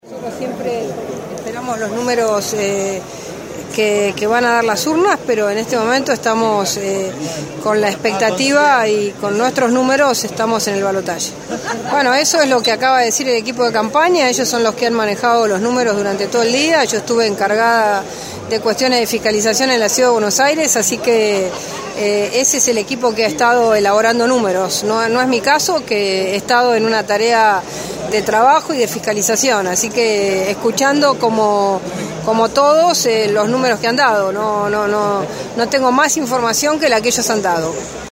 En declaraciones con Radio Gráfica, Patrica Bullrich, candidata a diputada nacional, afirmó que según sus números, están en el ballotage, aunque esperan espectantes el resultado con los datos oficiales.